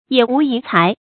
野無遺才 注音： ㄧㄜˇ ㄨˊ ㄧˊ ㄘㄞˊ 讀音讀法： 意思解釋： 見「野無遺賢」。